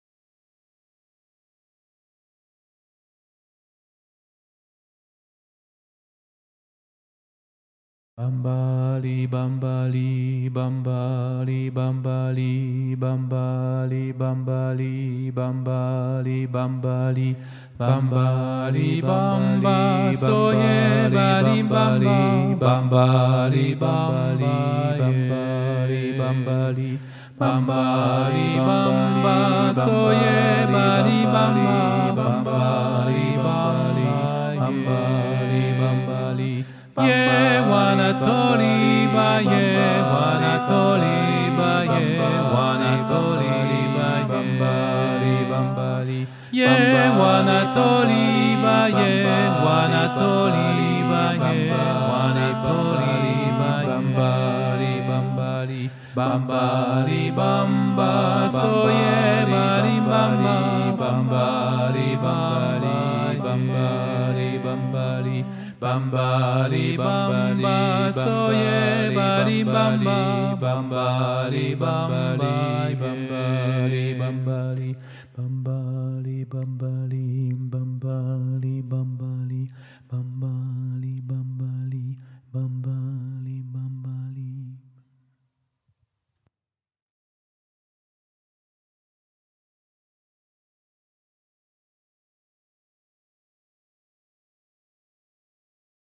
- Chant traditionnel du Sénégal
MP3 versions chantées